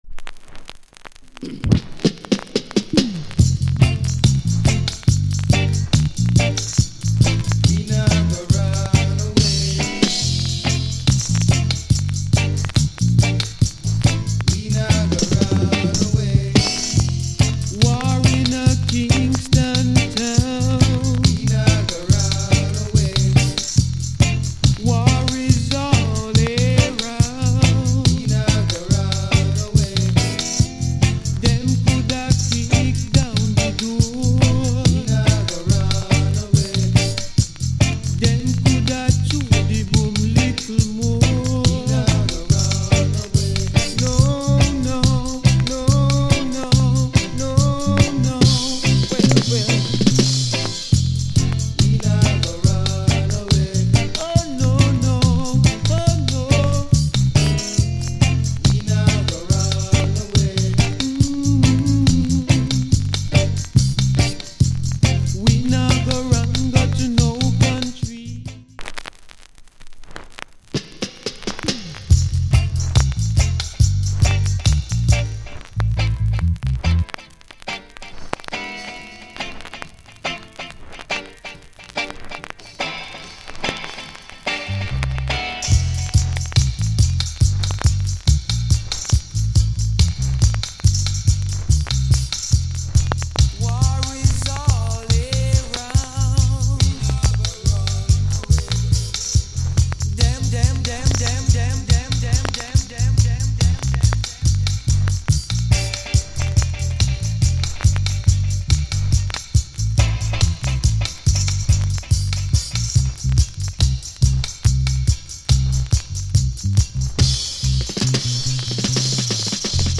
** 少しパチノイズ目立ちます。